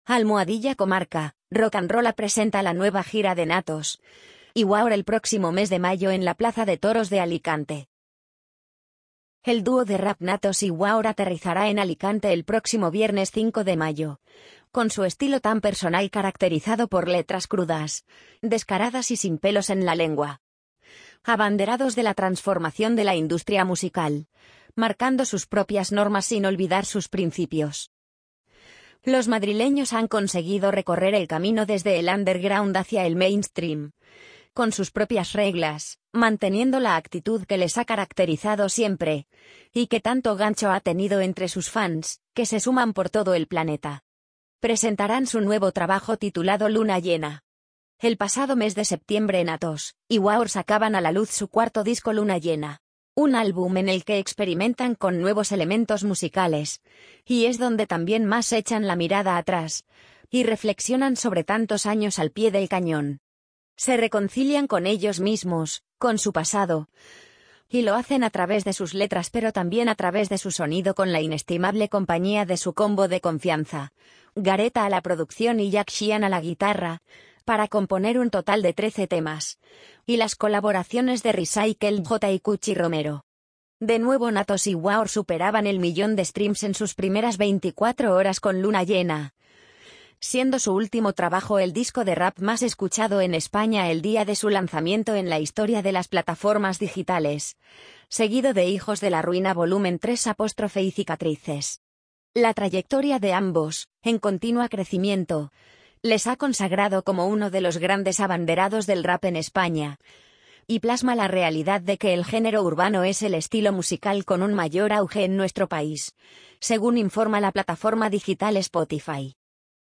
amazon_polly_65385.mp3